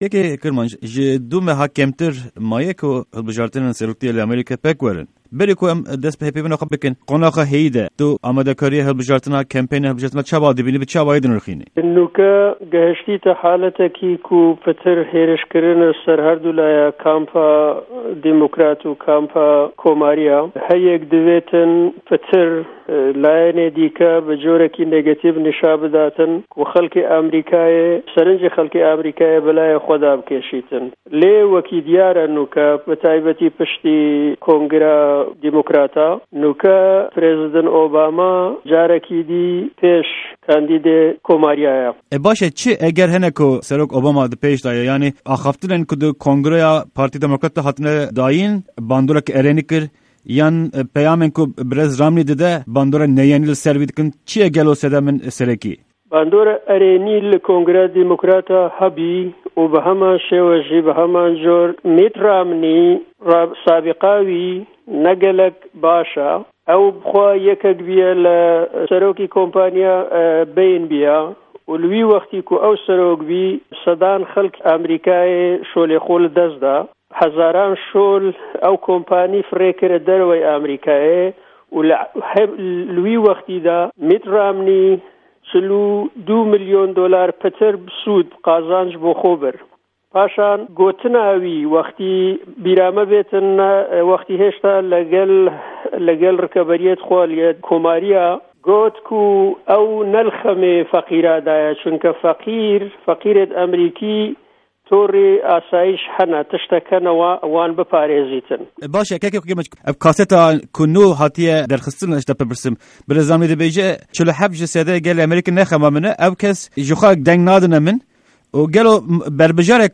Hevpeyvîneke Taybet ya Ser Hilbijartinên Amerîkî